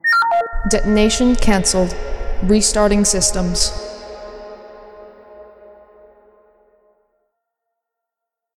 FemaleCanceled.ogg